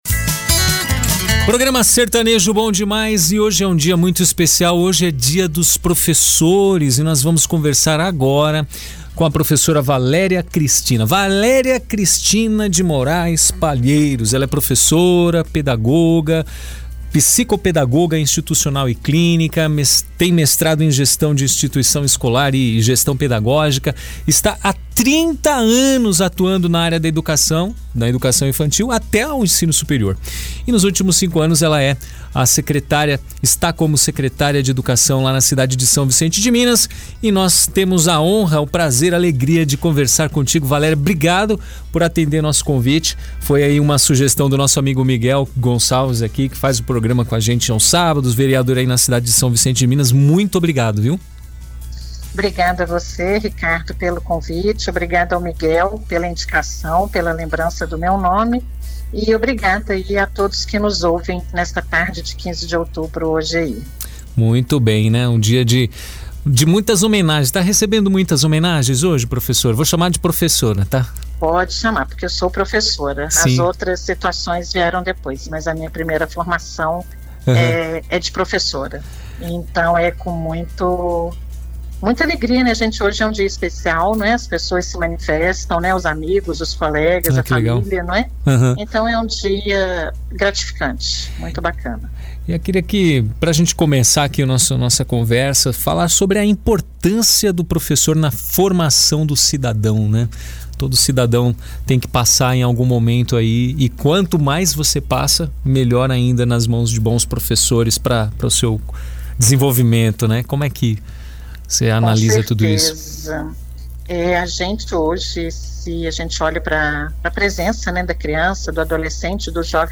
ENTREVISTA-PROFESSORA-VALERIA.mp3